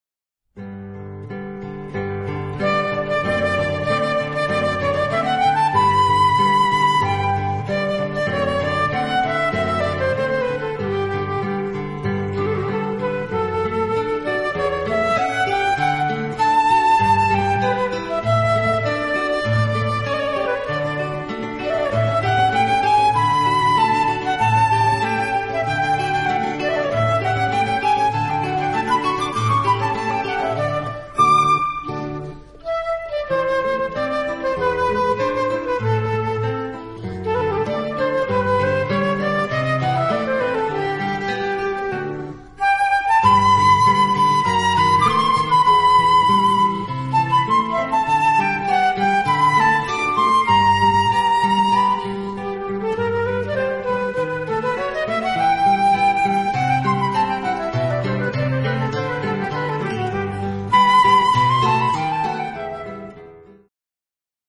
guitar Two seminal pieces for guitar and flute duet.